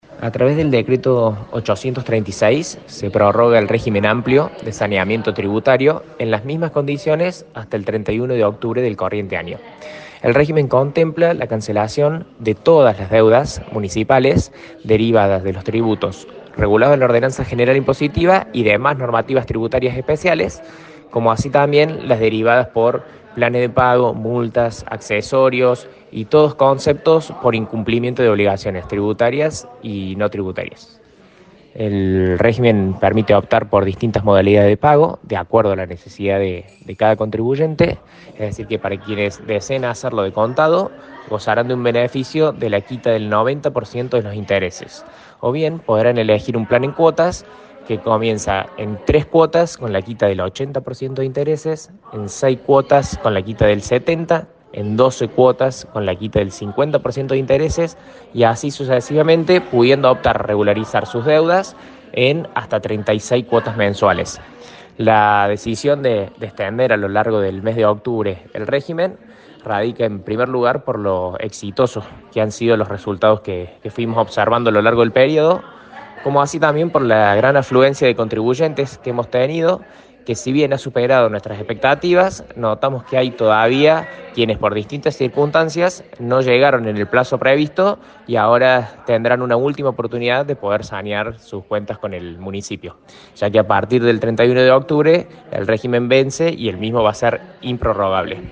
AUDIO – SUBSECRETARIO DE FINANZAS PABLO PERETTI